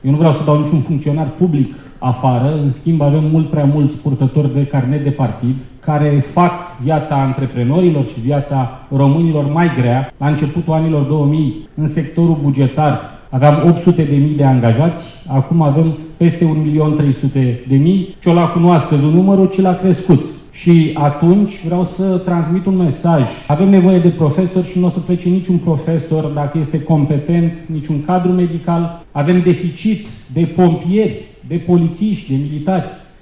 Preşedintele AUR, George Simion, candidat la preşedinţie, a afirmat în cadrul conferinţei Romanian Business Leaders, organizată la Bucureşti, că NU susţine disponibilizarea unei părţi a funcţionarilor publici.
George Simion a răspuns astfel antreprenorilor, care l-au întrebat dacă este în favoarea reducerii cheltuielilor statului sau a creşterii taxării, pentru a echilibra bugetul: